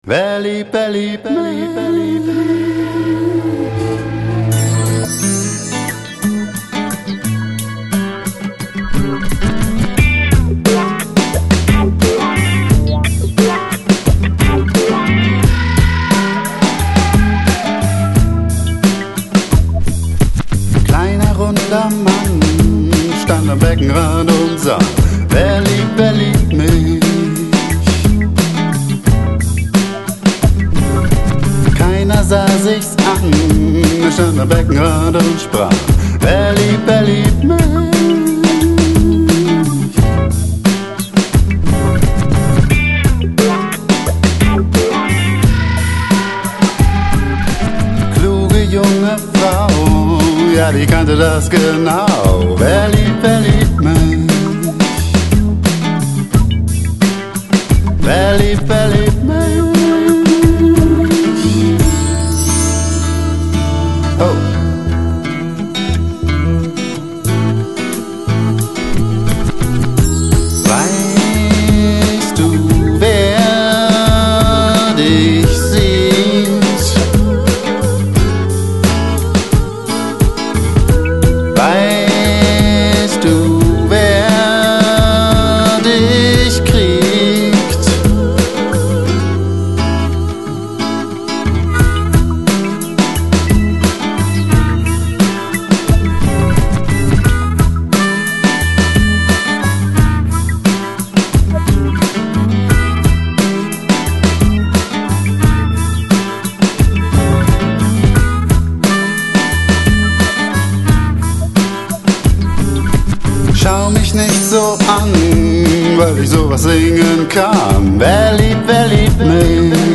Blues-Pop, d + b + g + voc + organ